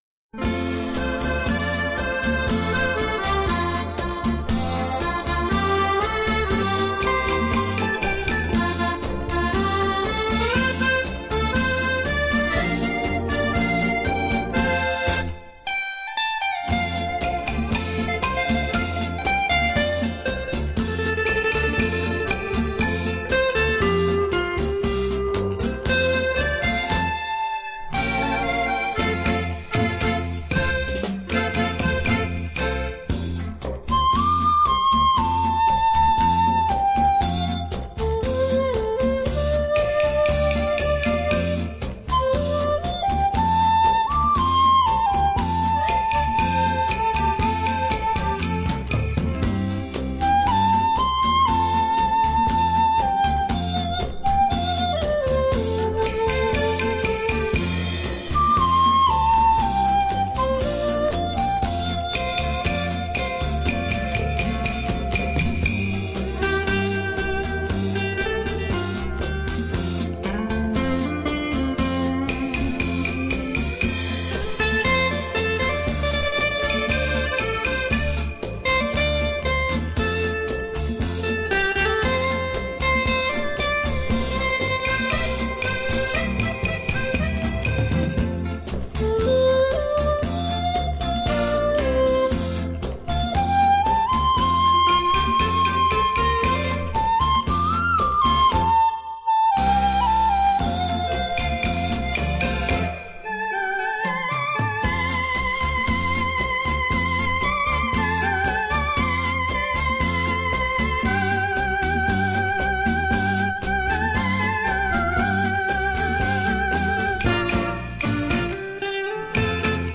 笛、电吉他、曼陀林、手风琴